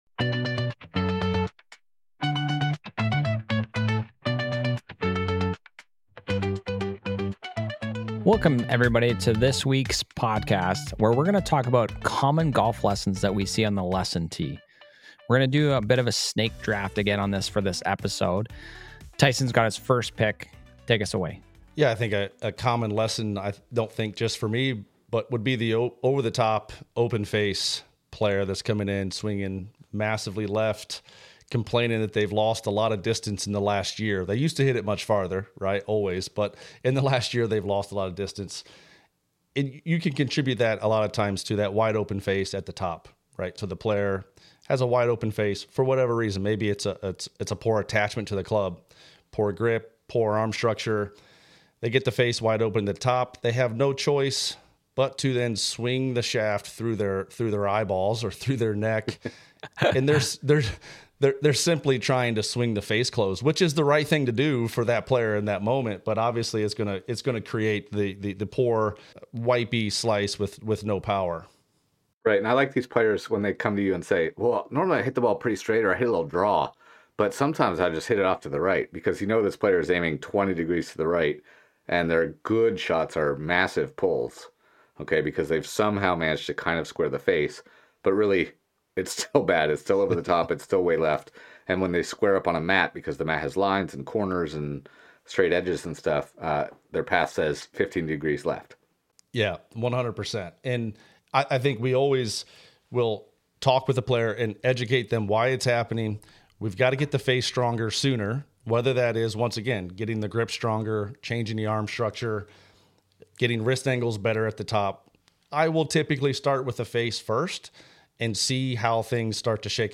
The Spin Axis is a conversation among golf coaches and instructors. We discuss the latest in teaching methods and techniques, equipment, training aids, and technology.